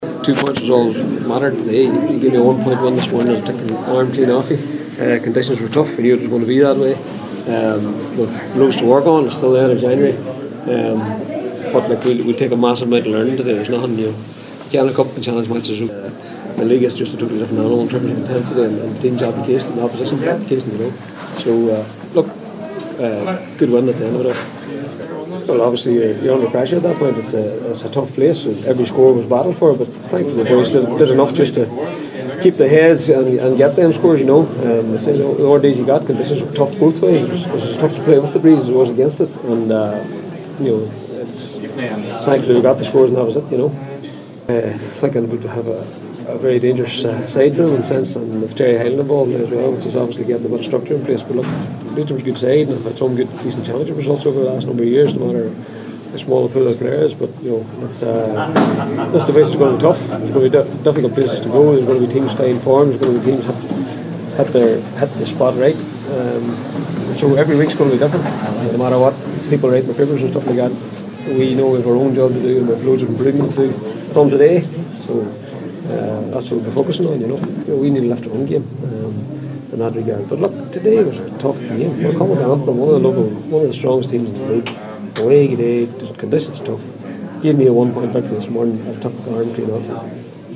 spoke with the media…